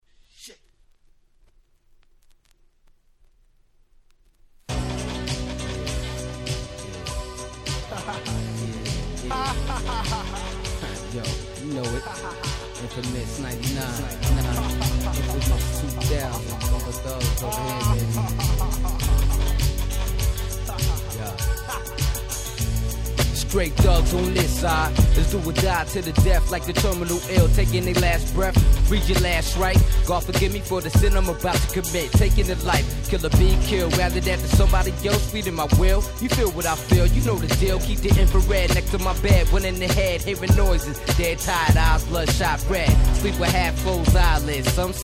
99' Smash Hit Hip Hop !!
ある意味このブッ飛び加減がサグ感を引き立たせる最高のスパイスになっております！